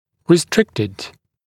[rɪ’strɪktɪd][ри’стриктид]ограниченный